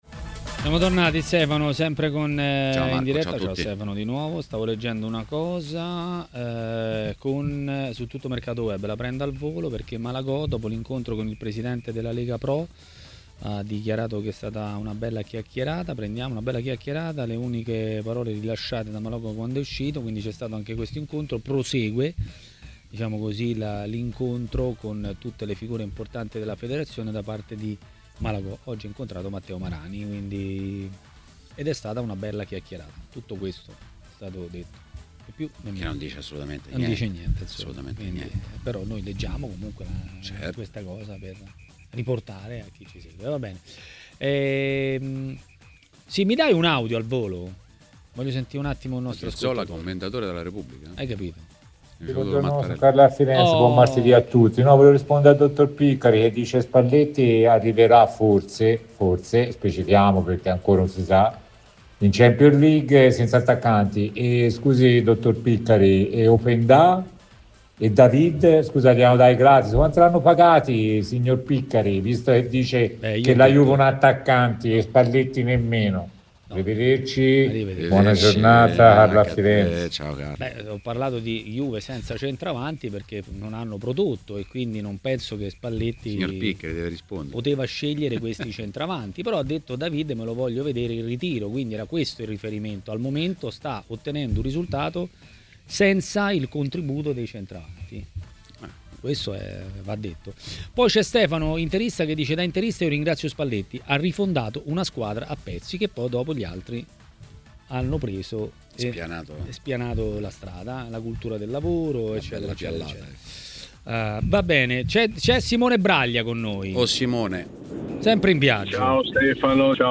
A commentare le notizie del giorno a TMW Radio, durante Maracanà, è stato l'ex calciatore Luigi Garzya.